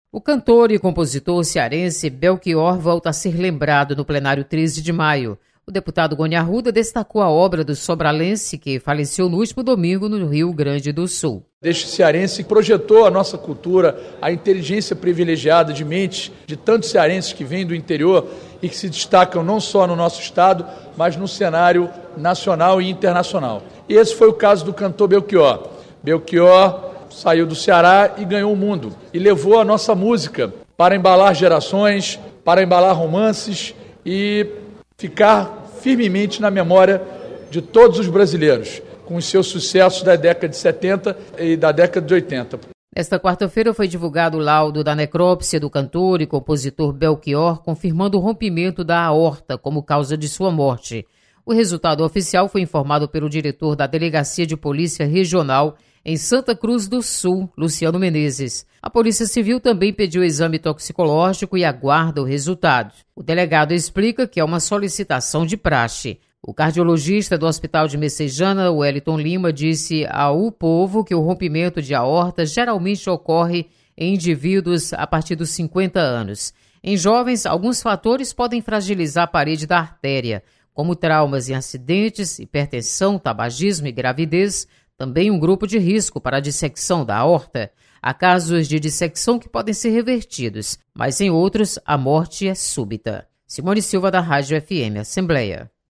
Deputado Gony Arruda lembra do legado deixado por Belchior para a cultura. Repórter